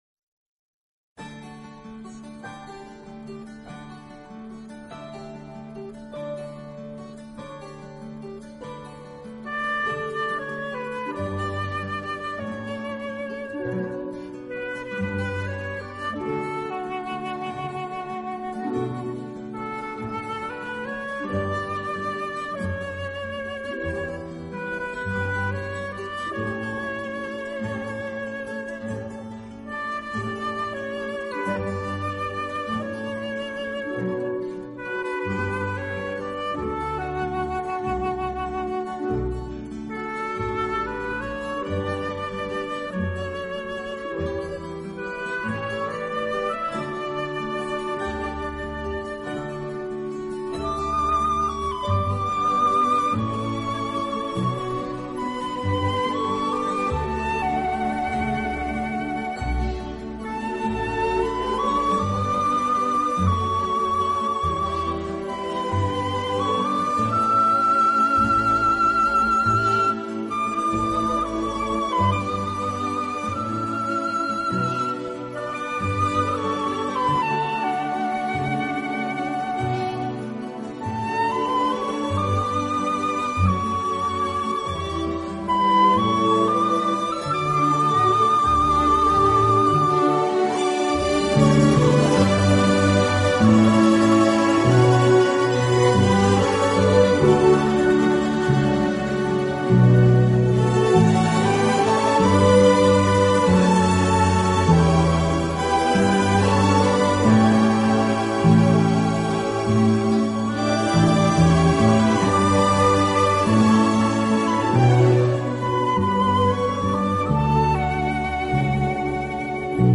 有的音乐改编自古典音乐经典片段、好莱坞浪漫巨片和流行金榜名曲。
十二集超过二百首流行音乐元素与世界各地风情韵味完美结合的音乐，